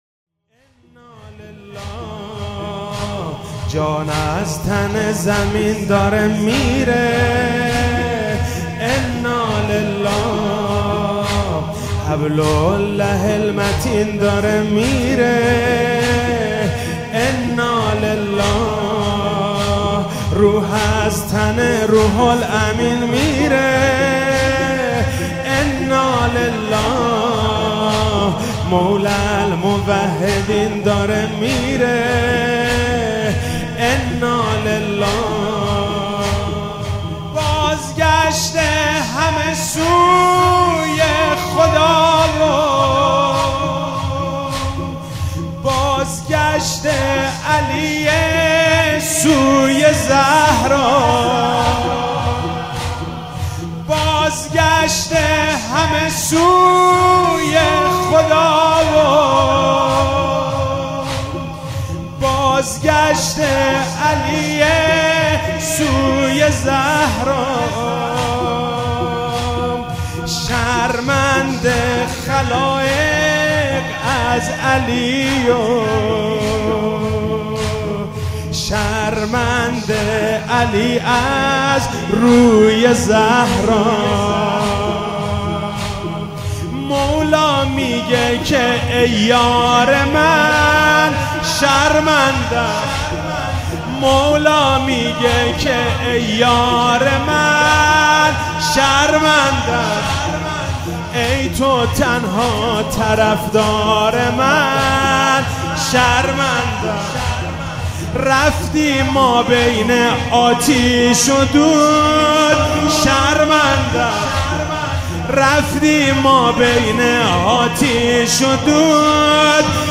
شب بیست و یکم رمضان 98 - زمینه - انالله جان از تن زمین داره میره